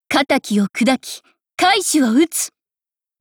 贡献 ） 协议：Copyright，其他分类： 分类:碧蓝航线:寰昌语音 您不可以覆盖此文件。